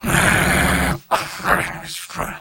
Robot-filtered lines from MvM. This is an audio clip from the game Team Fortress 2 .
Engineer_mvm_negativevocalization07.mp3